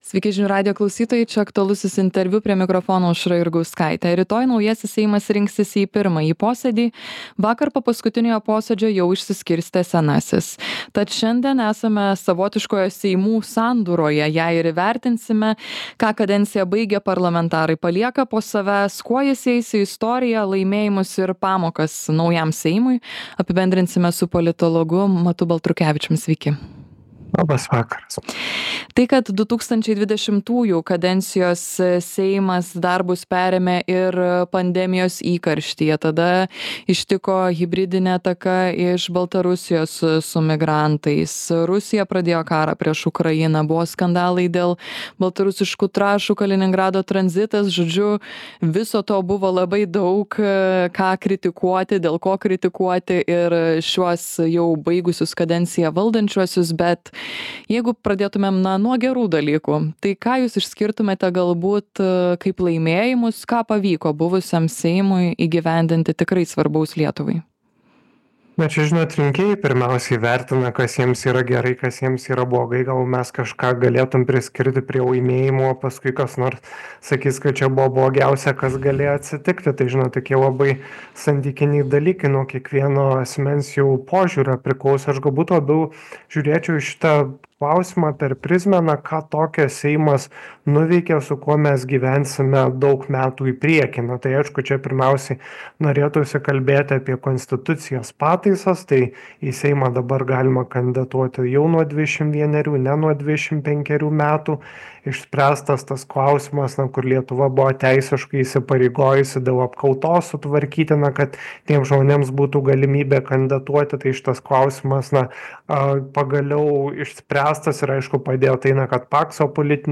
Aktualusis interviu